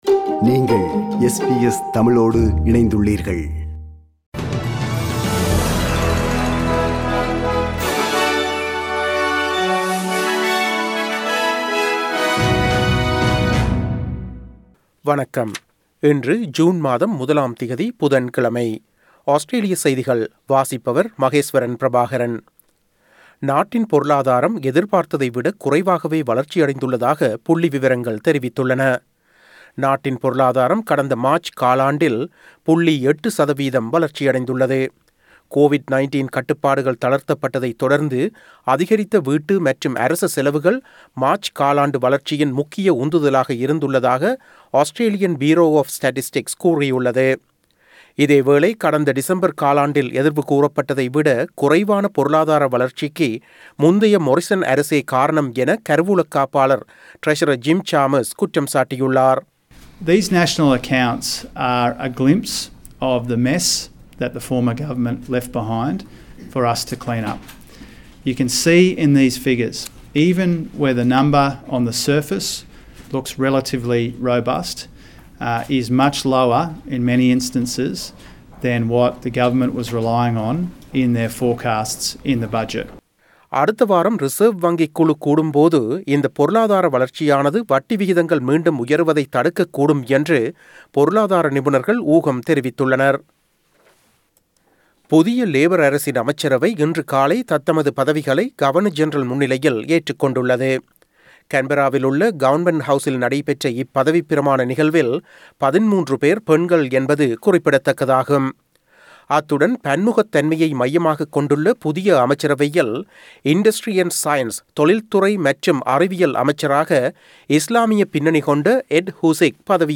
Australian news bulletin for Wednesday 01 June 2022.